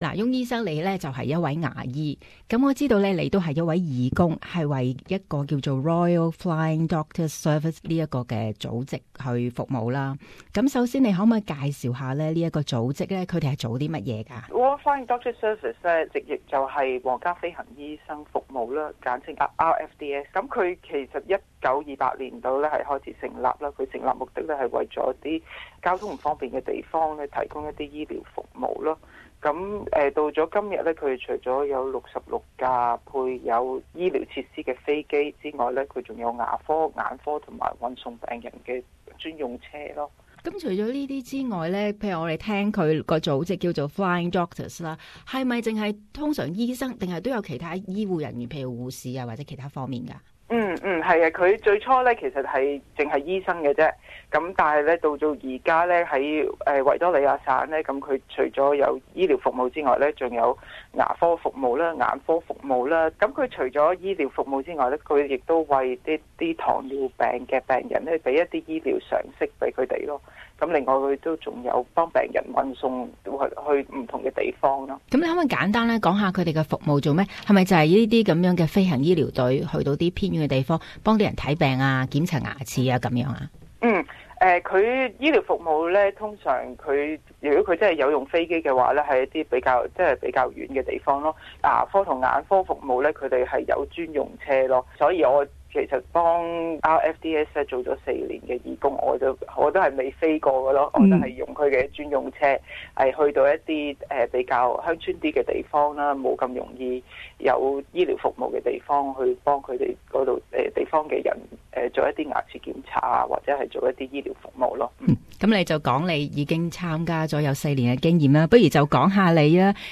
【社区专访】皇家飞行医生服务